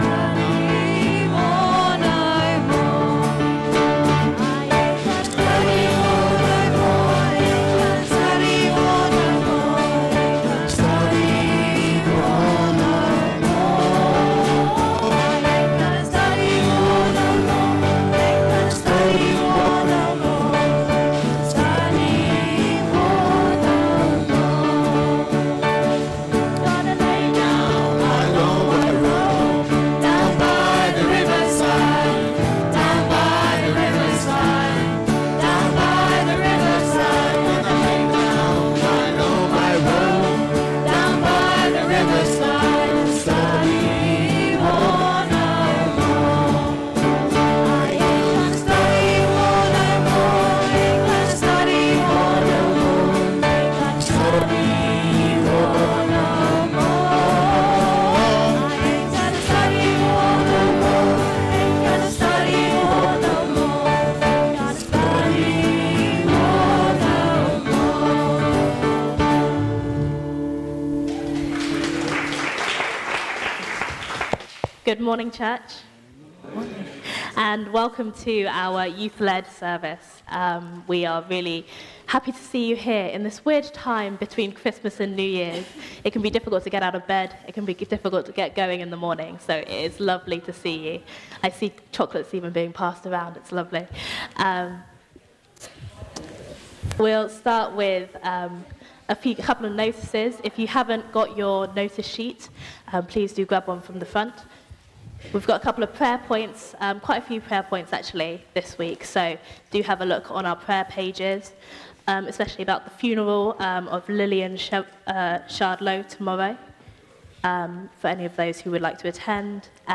Morning service - Sittingbourne Baptist Church